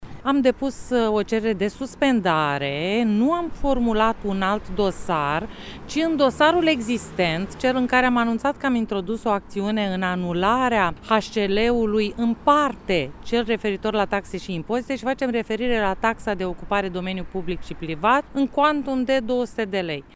Avocat